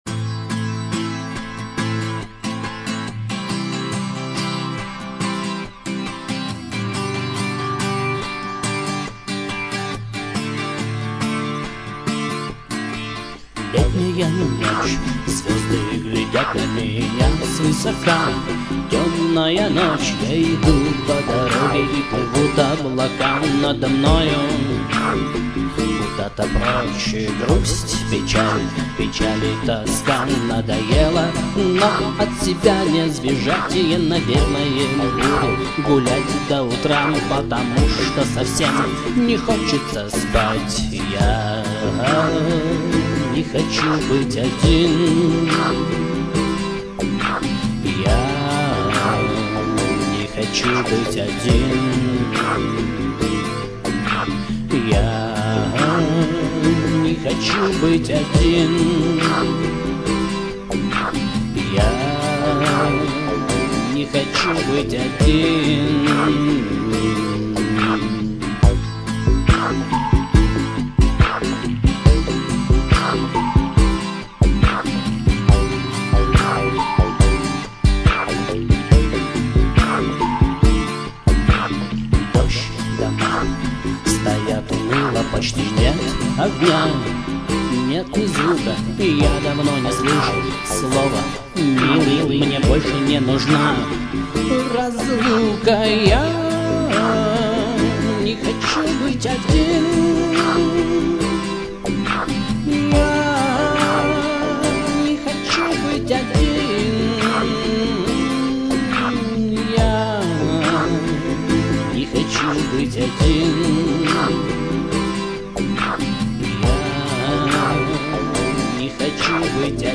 Армейские и дворовые песни под гитару
Такое раннее ещё не коммерцизированное , бесхитростное.